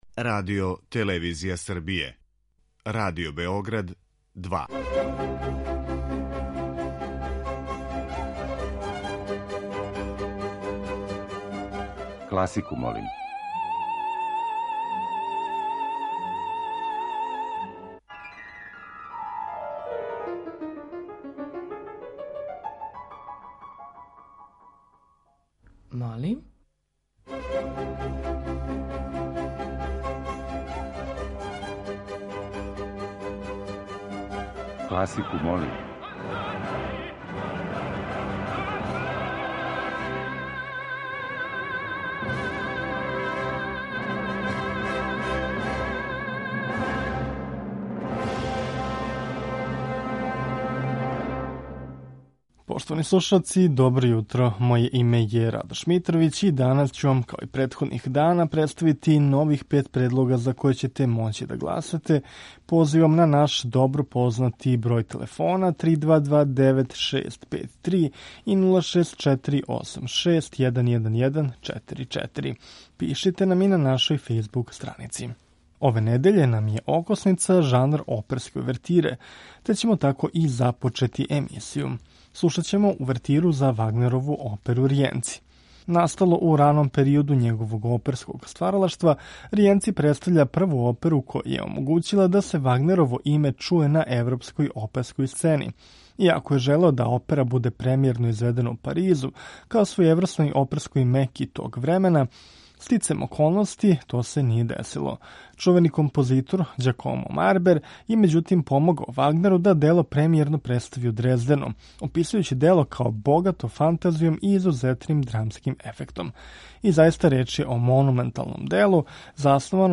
У емисији Класику, молим ове недеље окосница ће нам бити увертире познатих или мање познатих оперских остварења.